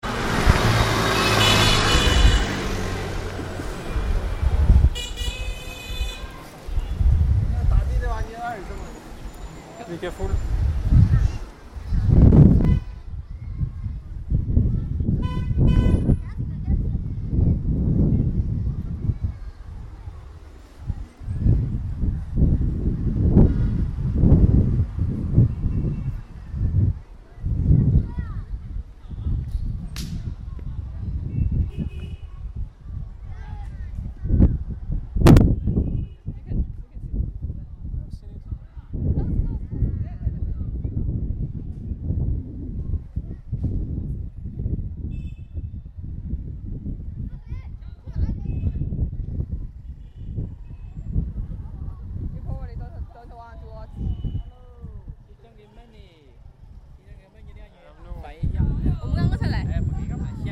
ambiance rues de Shenzen 1
ambiance de rues à Shenzen: klaxons, circulation, voix des passants